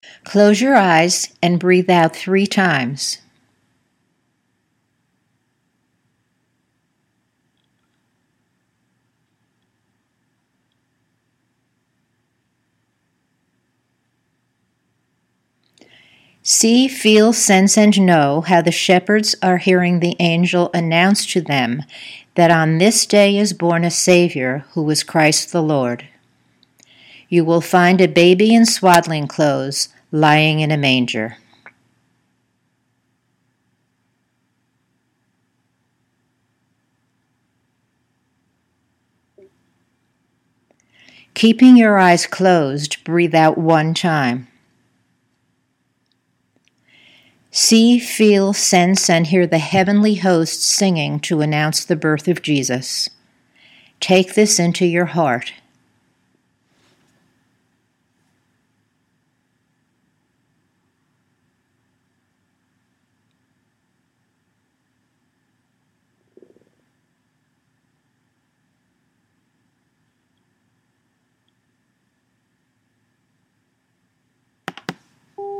When an Imagery instruction is complete, there are 15 seconds of silence on the tape for your Imagery to emerge.  When that time is over, you’ll hear a tone that is signaling you to breathe out one time and open your eyes.